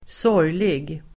Uttal: [²s'år:jlig]